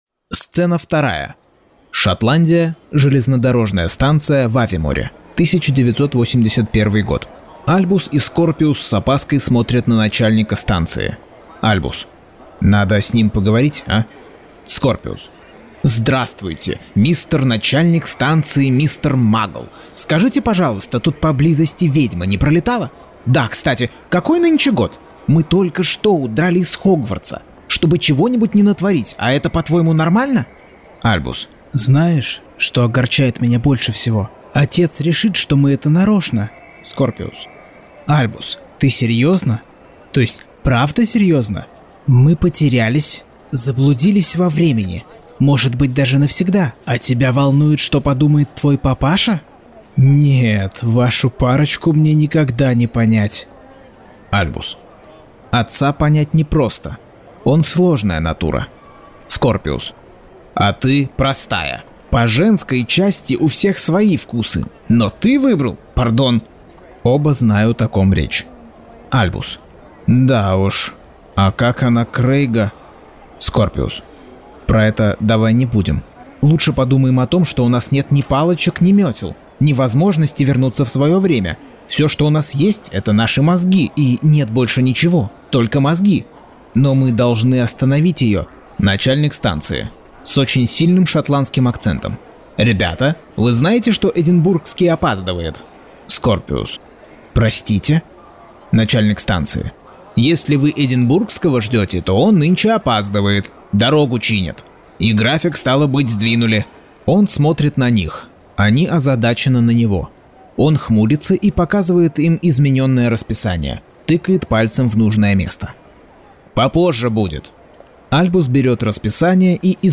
Аудиокнига Гарри Поттер и проклятое дитя. Часть 54.